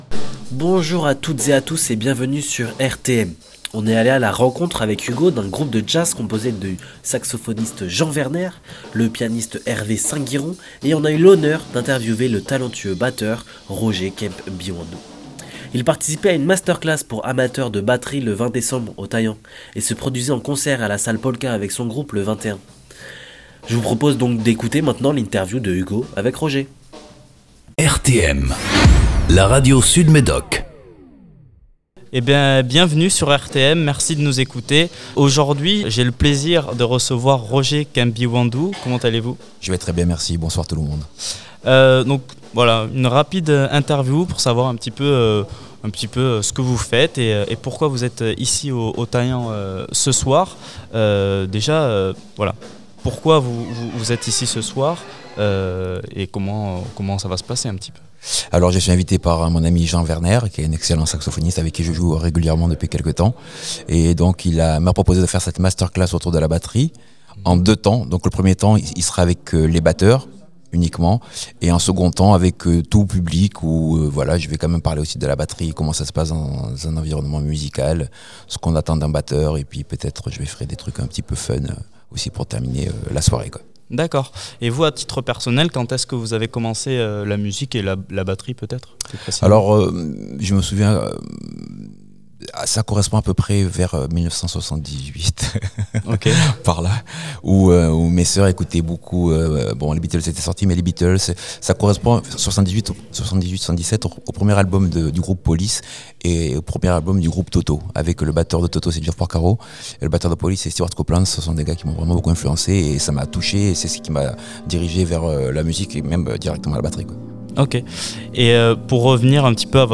Interview
Masterclass batterie